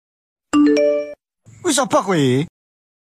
Nada Notif WA Lucu ‘Sopo Kuwi…’
Genre: Nada notifikasi Tag: nada notifikasi Ukuran file: 479 KB Dilihat: 5560 Views / 359 Downloads Detail: Suara unik dan bikin ngakak ini cocok banget dipake jadi nada dering WhatsApp atau nada notifikasi WA biar chat masuk jadi lebih seru.
nada-notif-wa-lucu-sopo-kuwi.mp3